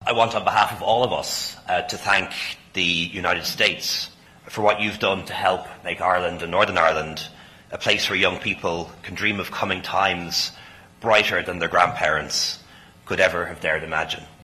At a lunch in Washington, Taoiseach Leo Varadkar said he was grateful to America for its role in the peace process……………..